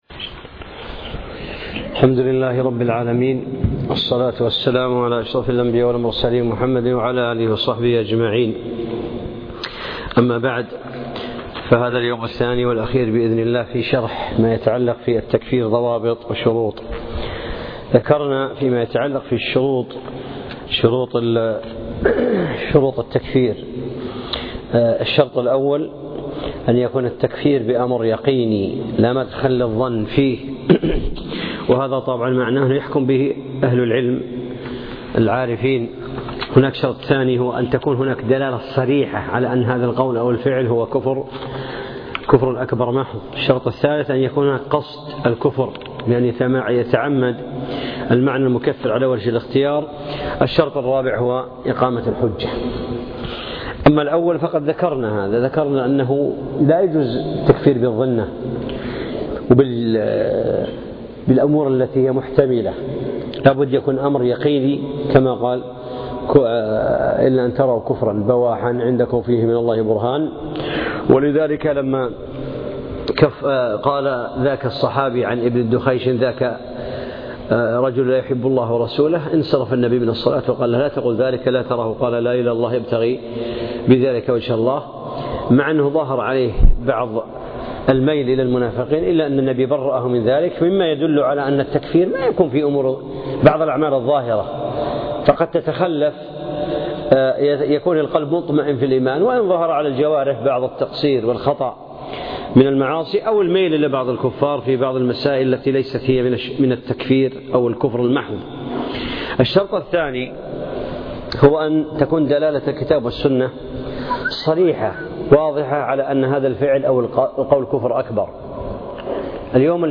الدرس الثالث والأخير